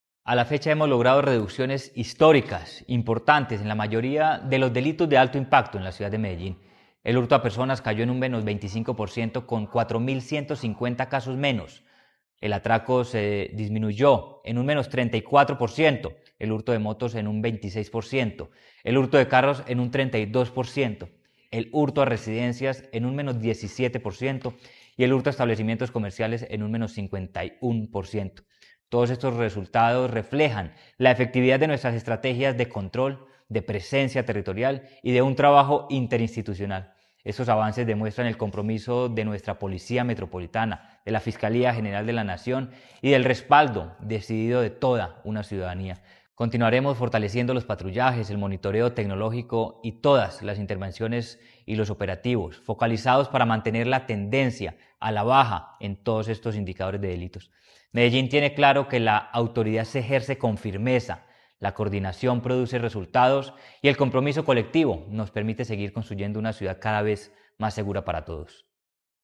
Declaraciones-del-secretario-de-Seguridad-y-Convivencia-Manuel-Villa-Mejia.mp3